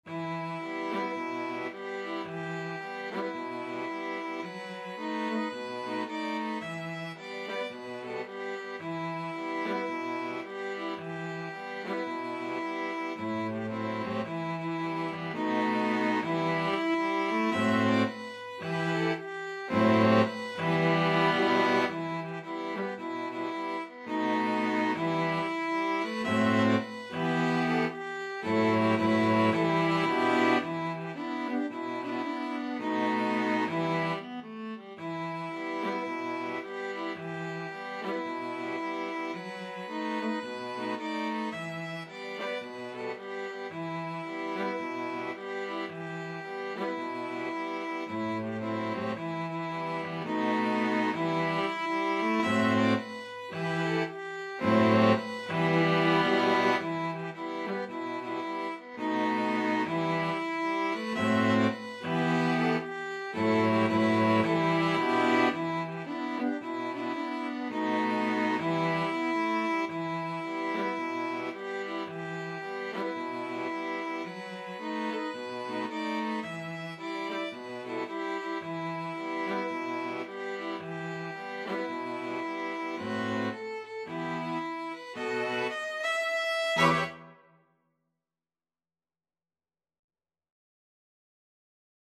Violin 1Violin 2ViolaCello
E minor (Sounding Pitch) (View more E minor Music for String Quartet )
4/4 (View more 4/4 Music)
Moderate Gospel , Swung = c.110
String Quartet  (View more Easy String Quartet Music)
Traditional (View more Traditional String Quartet Music)